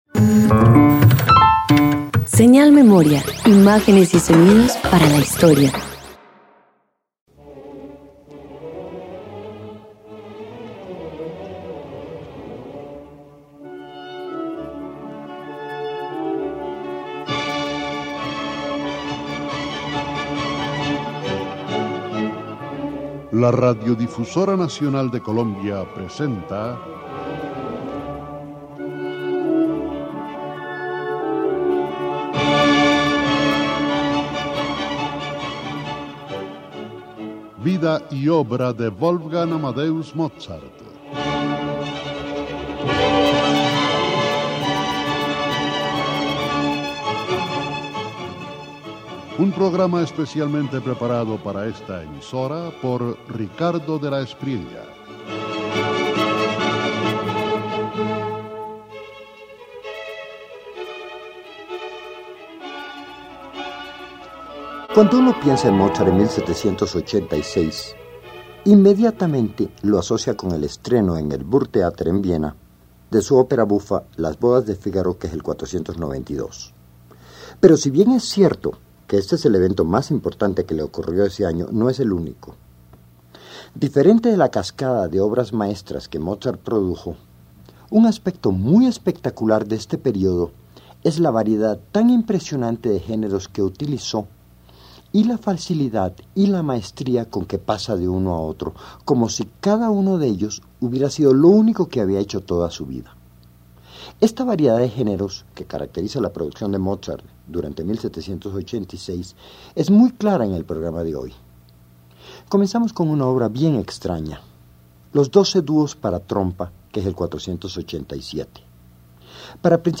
252 Trío en Mi Bemol Mayor para clarinete viola y piano_1.mp3